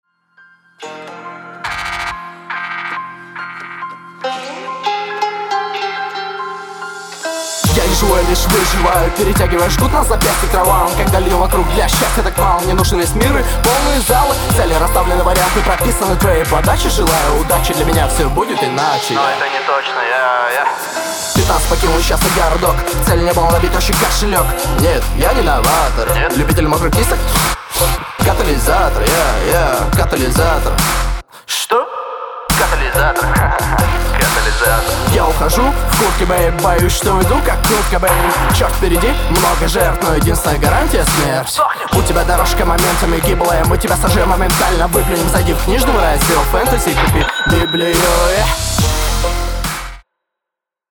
Рифмуешь предсказуемо, техника неоднозначная - читаешь отрывисто, много лишних пауз